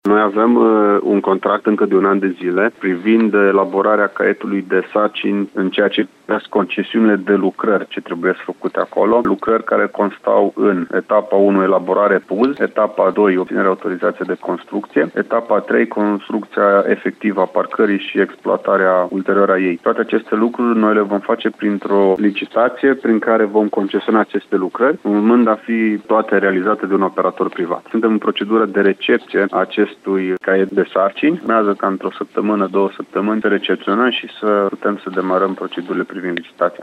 Potrivit vicepreședintele Consiliului Județean Alexandru Proteasa acesta este cel mai optimist scenariu.
Alexandru-Proteasa-1.mp3